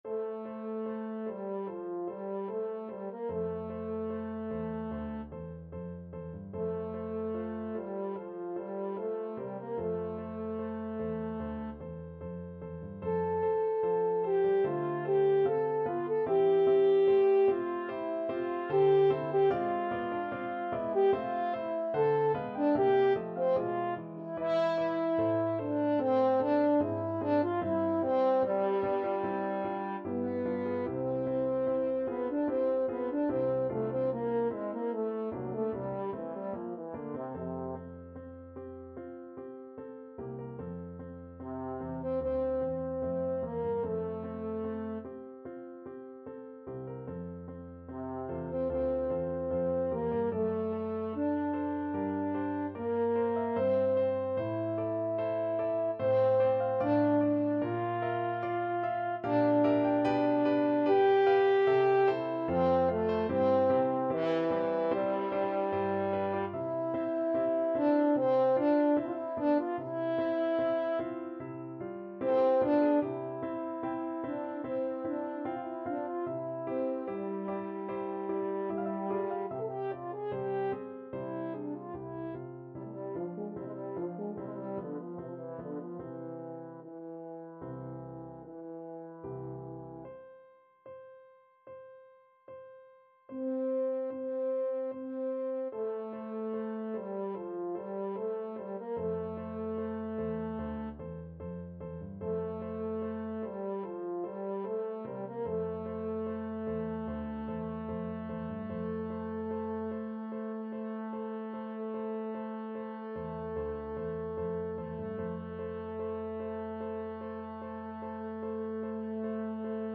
French Horn
4/4 (View more 4/4 Music)
F major (Sounding Pitch) C major (French Horn in F) (View more F major Music for French Horn )
~ = 74 Moderato
Classical (View more Classical French Horn Music)